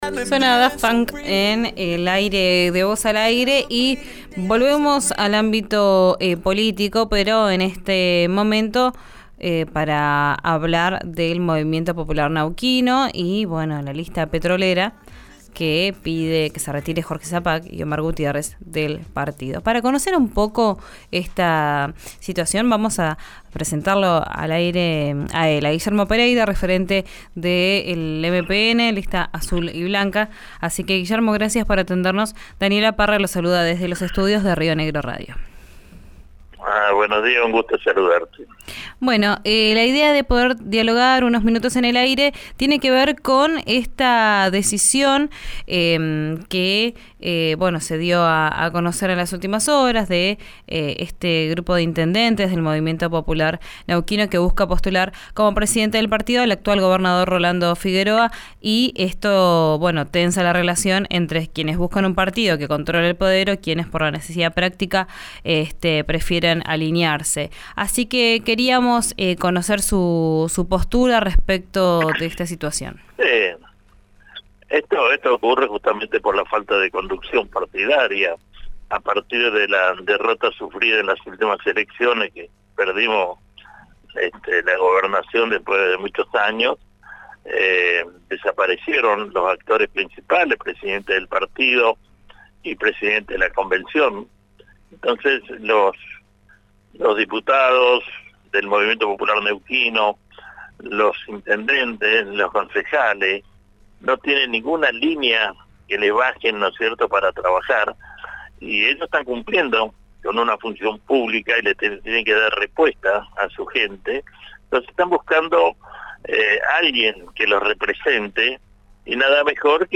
El líder de la lista Azul y Blanca le pidió que dé un paso al costado y permita que conduzcan las nuevas generaciones. Escuchá la entrevista completa en RÍO NEGRO RADIO.